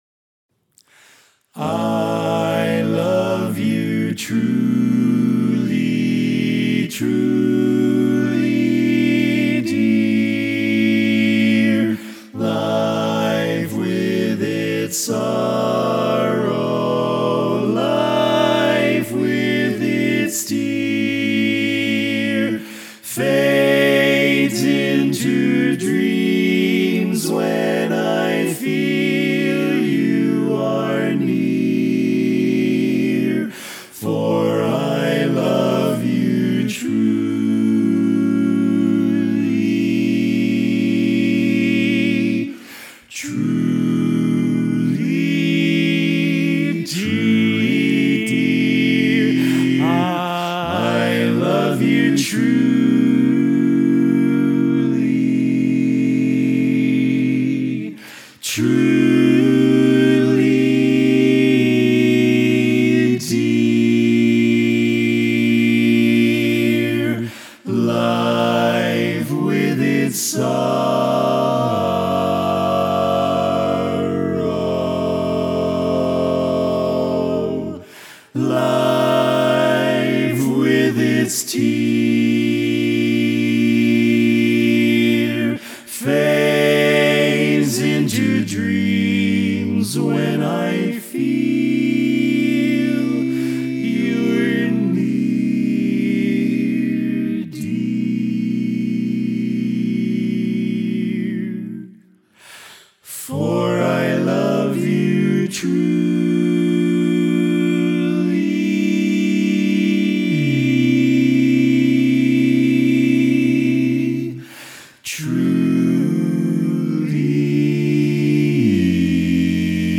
Kanawha Kordsmen (chorus)
Barbershop
Ballad
Full Mix